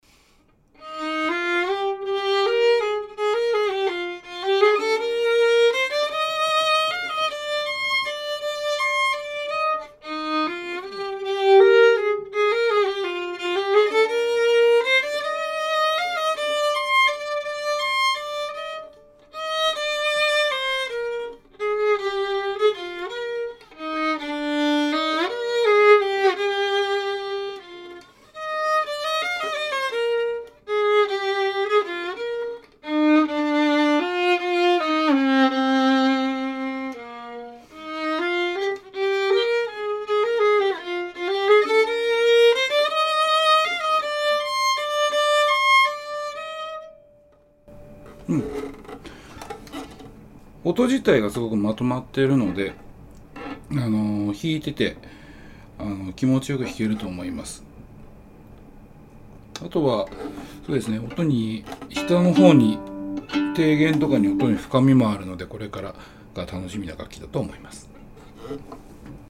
深みのある音色でバランス良くで気持ち良く鳴ってくれます。
音質：高温域は明るくて・明確で・きれいで・またエネルギッシュです。 より低音域は深くて・強くて・またパワフルです。